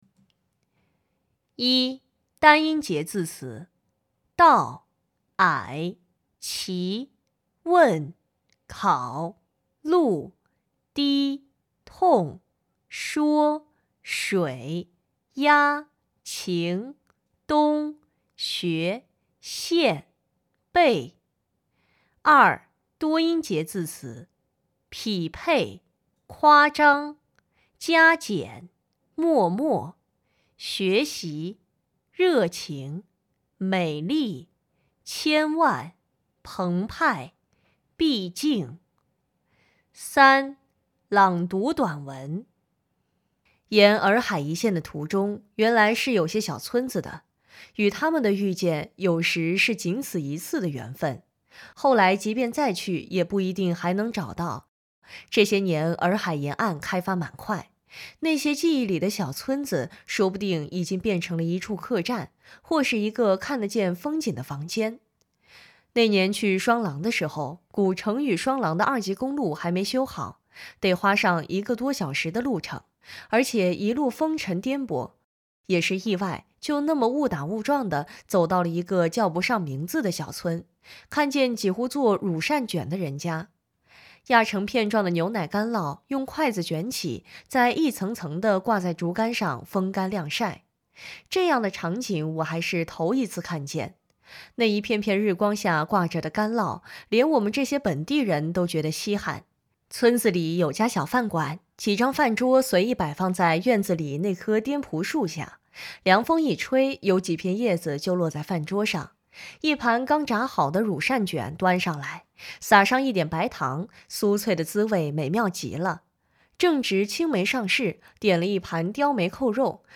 领读课件
（领读音频于文末，可播放跟读练习）